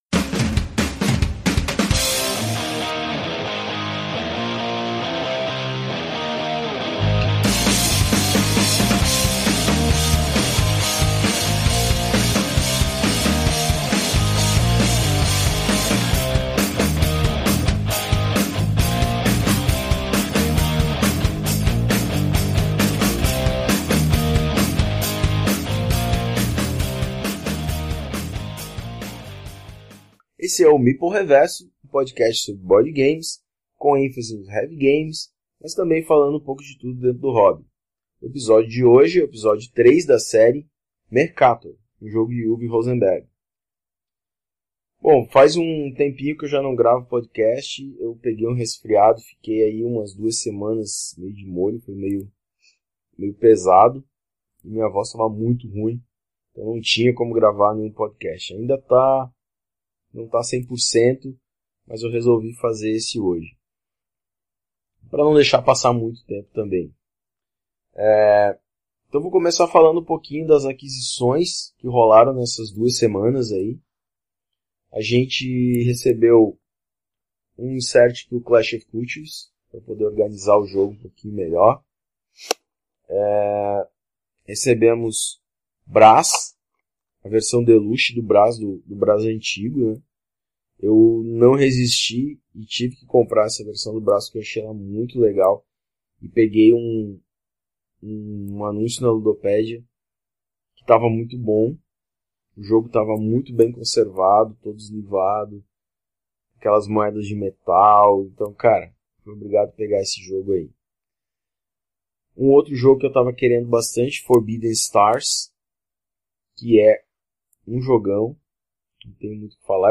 Vou dar uma dica: põe uma música de background, pois se não fica muito monótono só ouvir vc falando :/